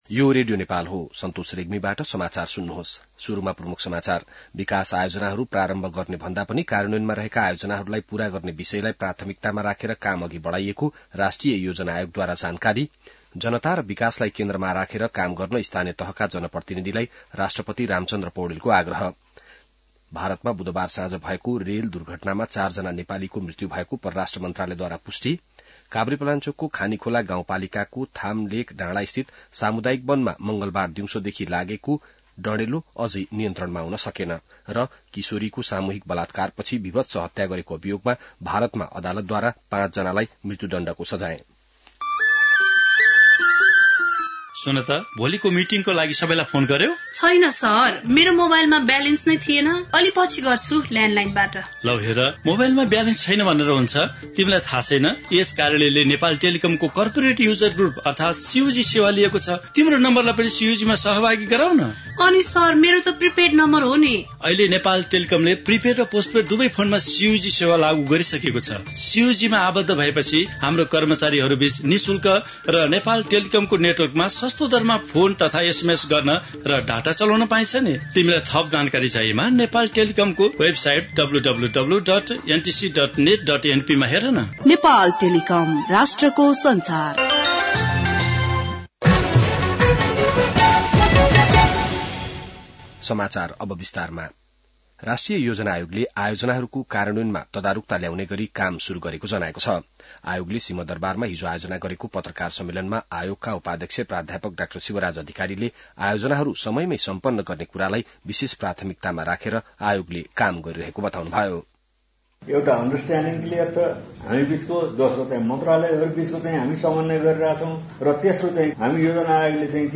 बिहान ७ बजेको नेपाली समाचार : १२ माघ , २०८१